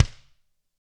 Index of /90_sSampleCDs/ILIO - Double Platinum Drums 1/CD4/Partition A/GRETSCHKICKD